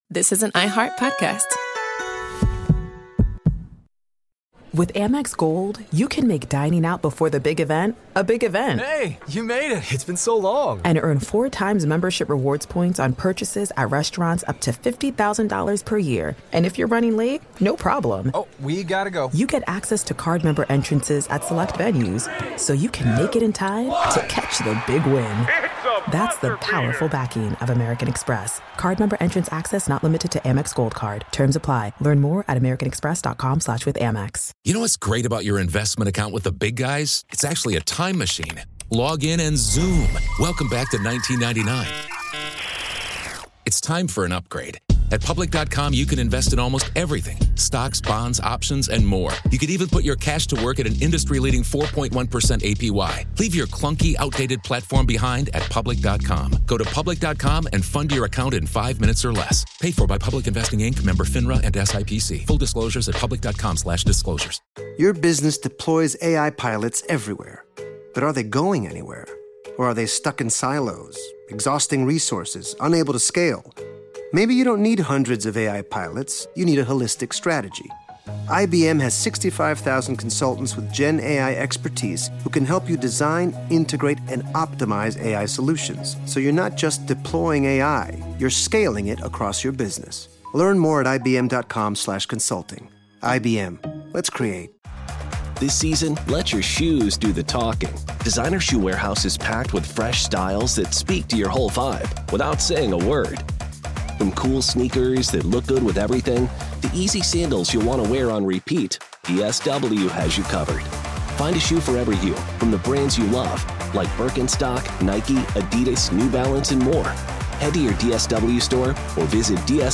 On this episode of Our American Stories, why did the capitalist United States ally with the Soviet Union and its dictator, Stalin? The late, great Stephen Ambrose answers that question and shares a World War II story that begins in January 1943 and ends with the Allies' decision to pursue unconditional surrender.